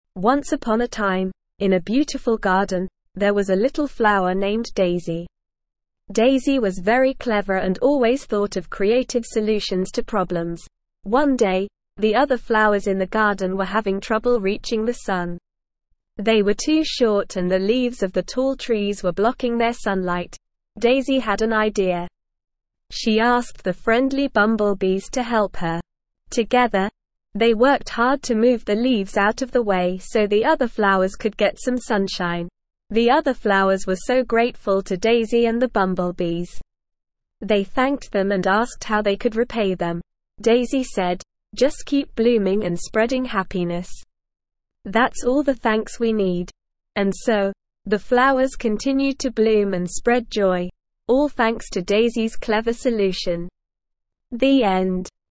Slow
ESL-Short-Stories-for-Kids-SLOW-reading-The-Clever-Flower.mp3